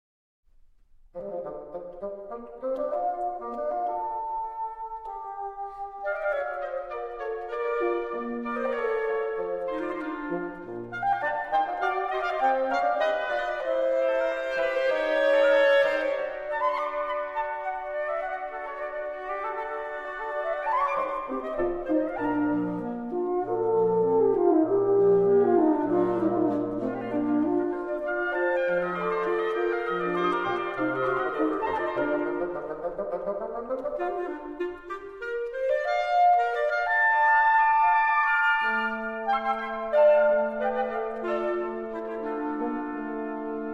Schulstück für Bläserquintett